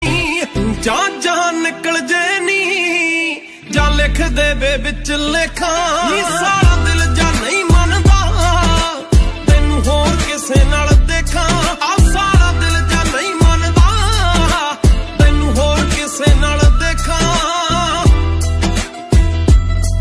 soulful rendition